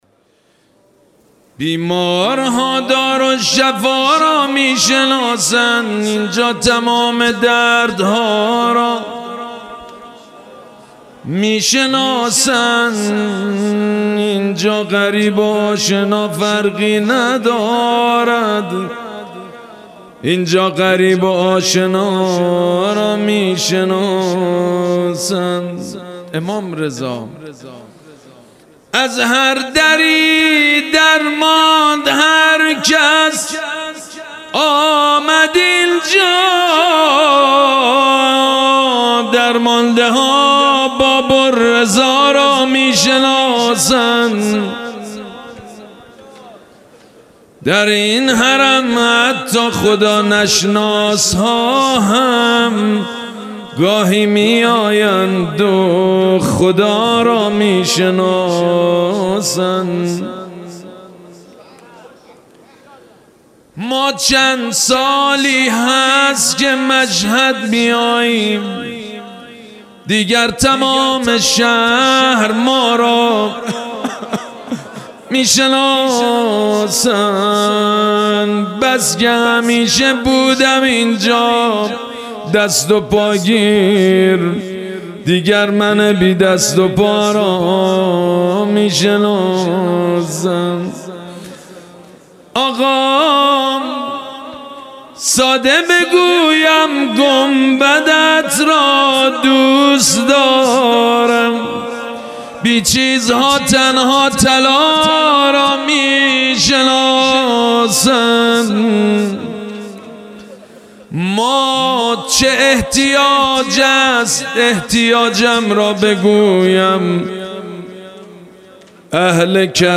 شعرخوانی - بیمارها دارالشفا را میشناسند